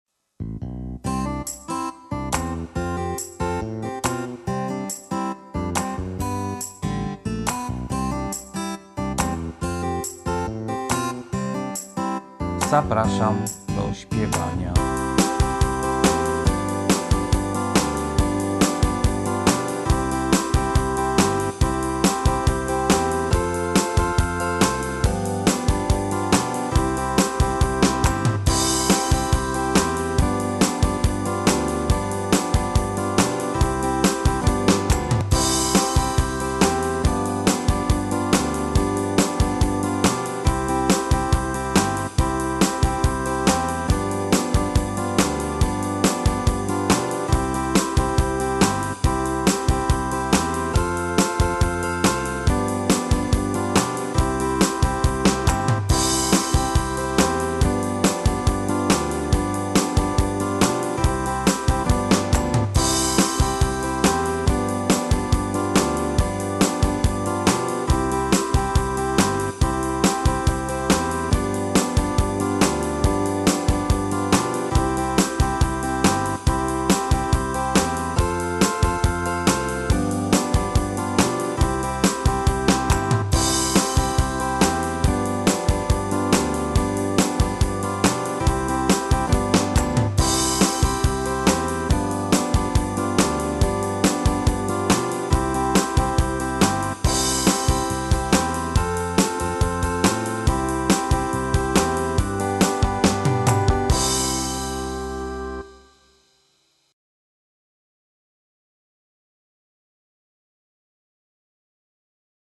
Wersja z linią bez linii melodycznej